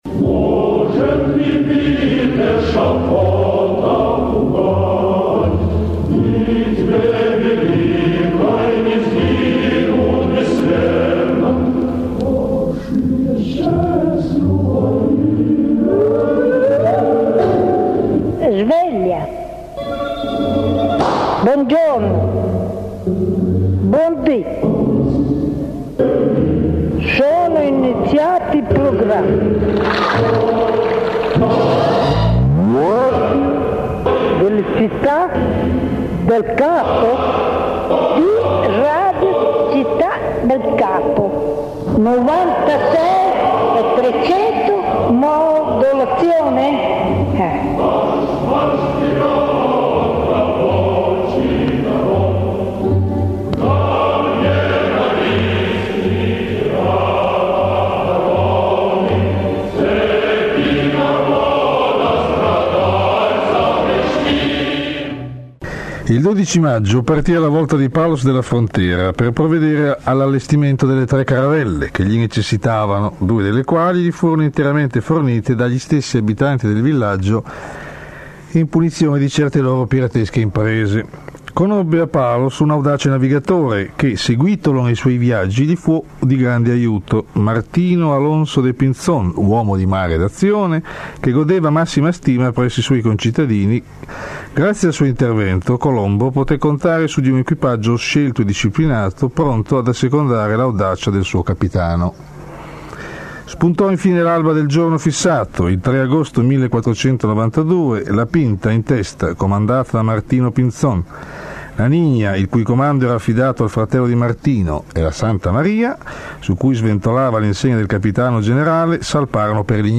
Un piccolo regalo per voi: il debutto, ovvero l’apertura dei programmi di Radio Città del Capo nell’ottobre 1987.
prima_trasmissione.mp3